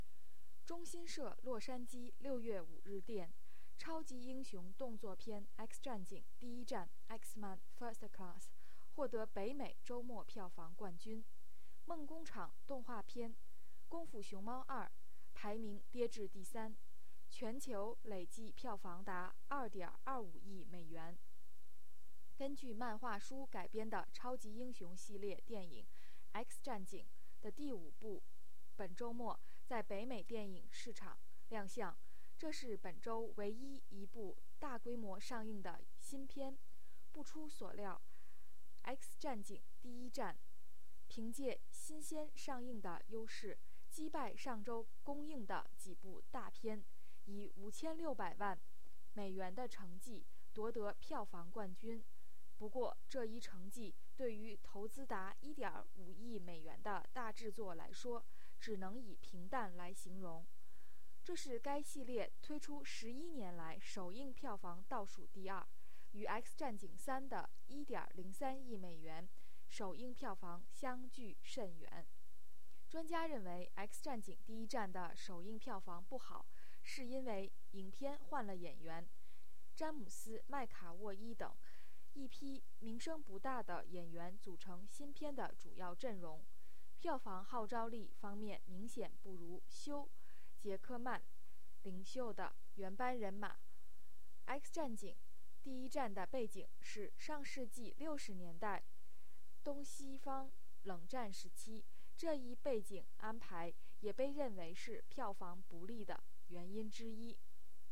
1. When and where was the news released?